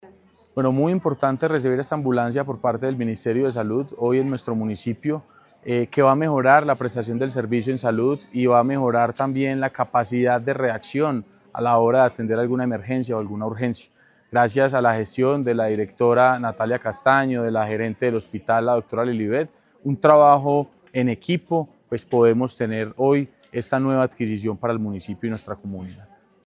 Sebastián Merchán, alcalde de Aranzazu.